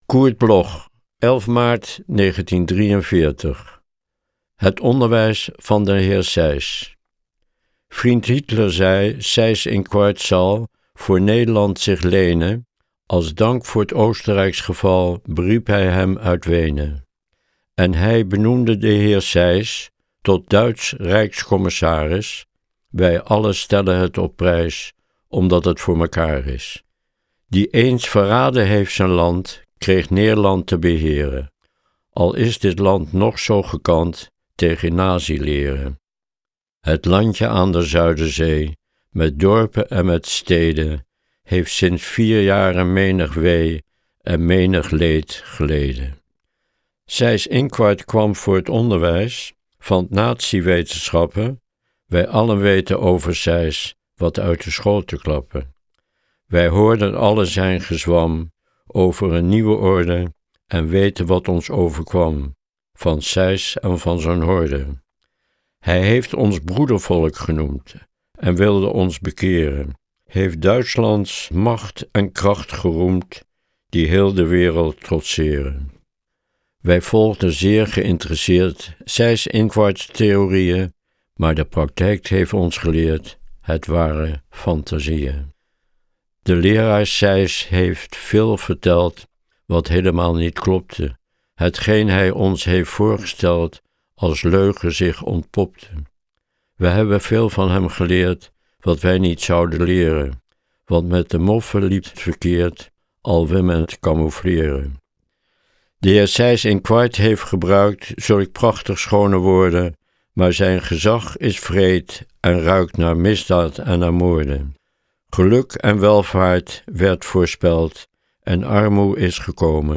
Aufnahme: Studio Levalo, Amsterdam · Bearbeitung: Kristen & Schmidt, Wiesbaden